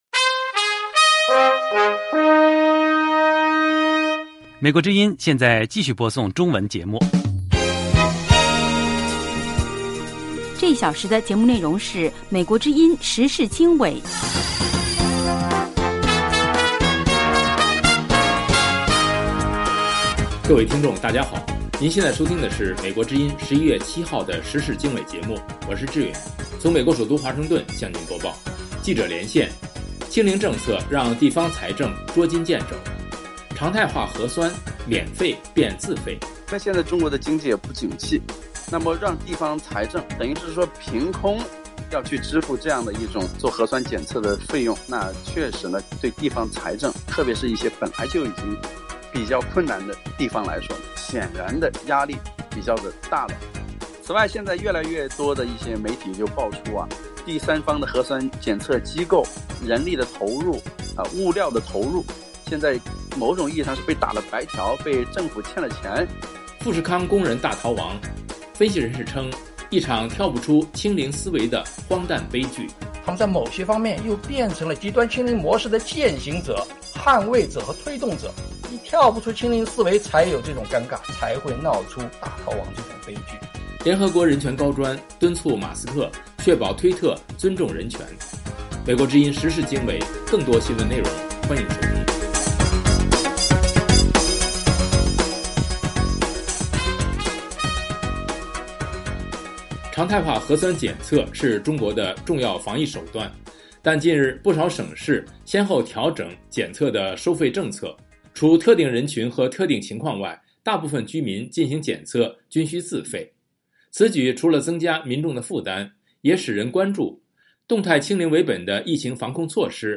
时事经纬(2022年11月7日)：1/记者连线：清零政策让地方财政捉襟见肘 常态化核酸免费变自费。2/富士康工人大逃亡，分析人士：一场跳不出清零思维的荒诞悲剧。